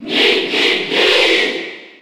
Mii_Cheer_French_NTSC_SSB4.ogg.mp3